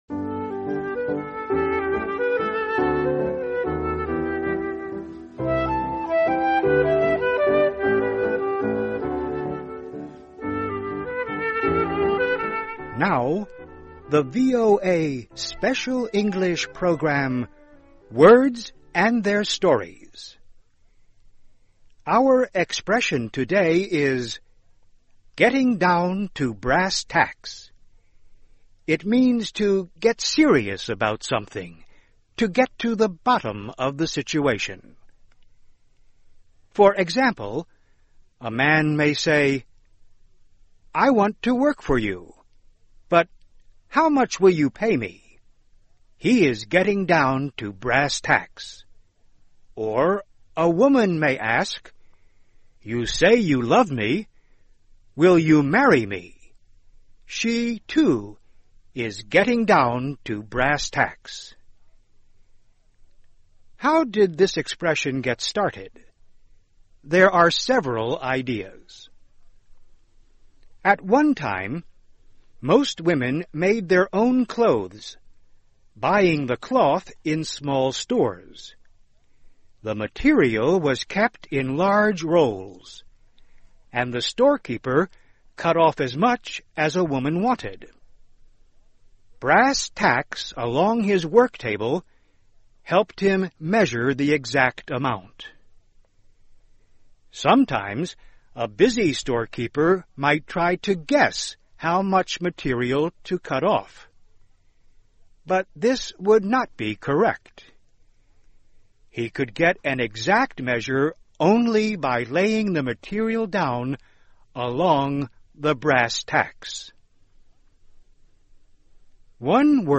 VOA慢速英语2013 让我们转入正题 听力文件下载—在线英语听力室